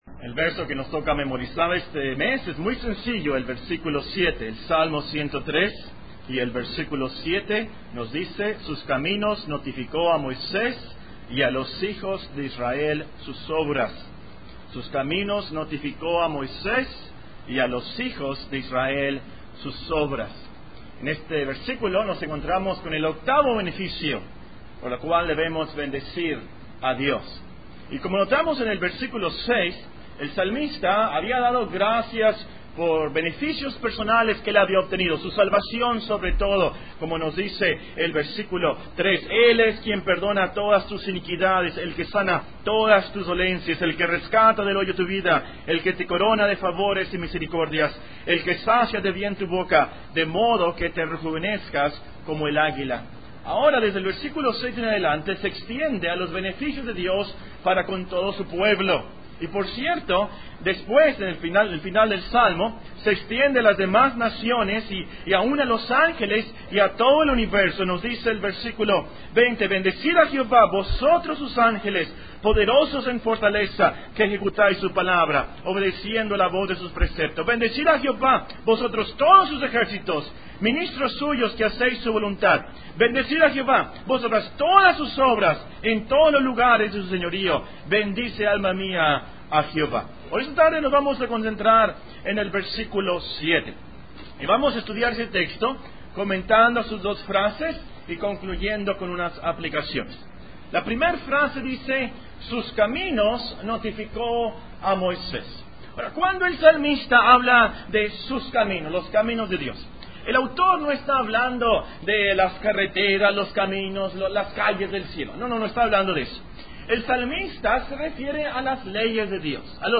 Serie de sermones General